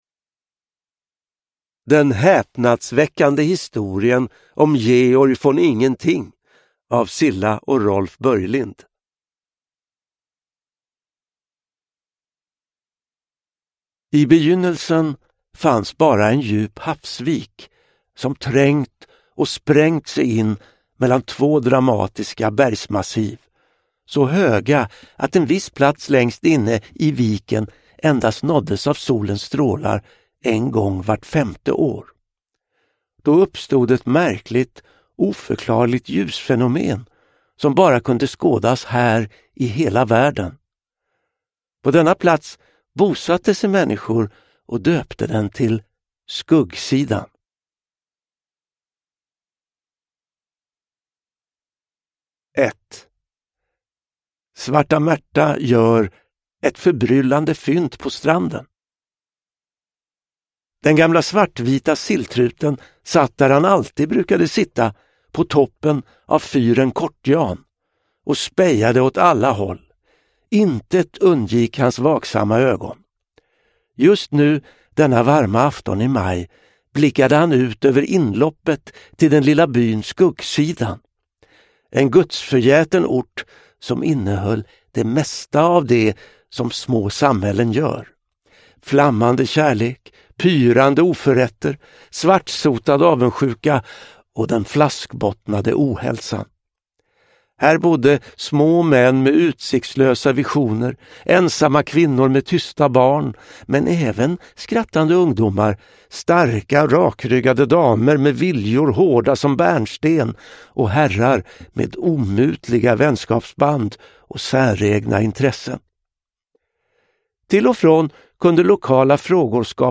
Den häpnadsväckande historien om Georg von Ingenting – Ljudbok
Uppläsare: Johan Ulveson